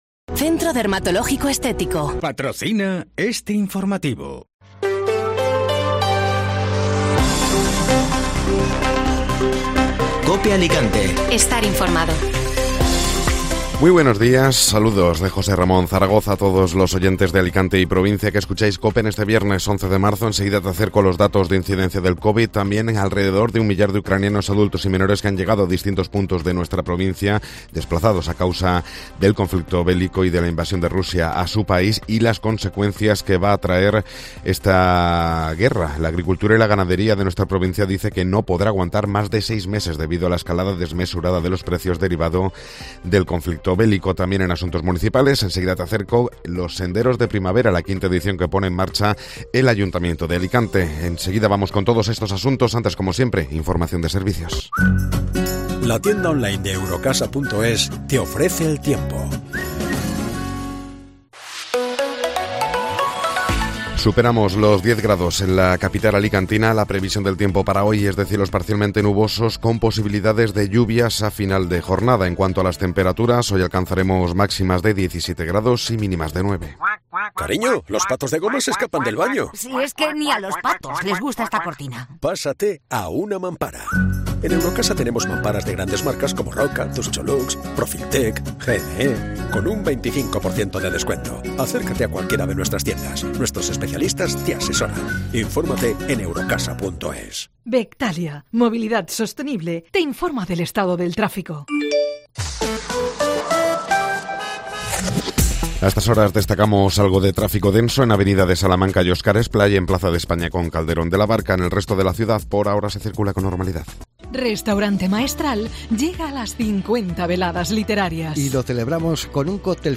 Informativo Matinal (Viernes 11 de Marzo)